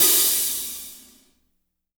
-16  HAT11-L.wav